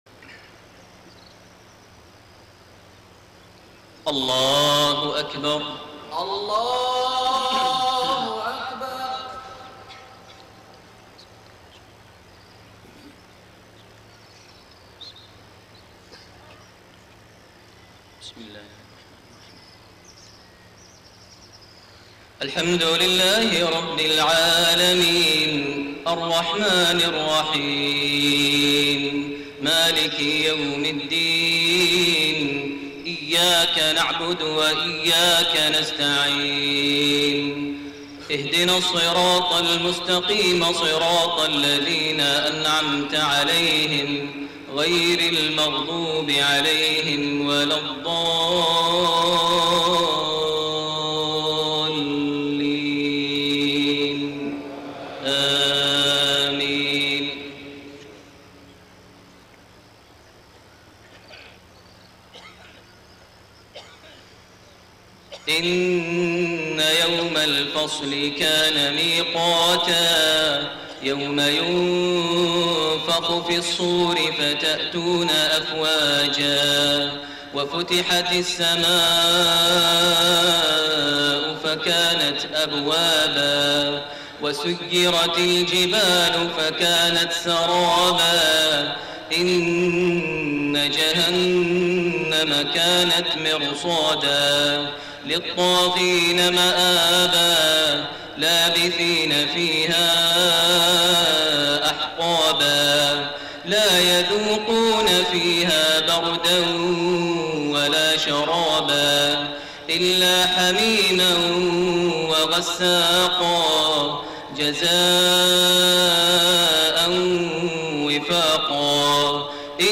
صلاة المغرب1-6-1431 من سورة النبأ 17-40 > 1431 هـ > الفروض - تلاوات ماهر المعيقلي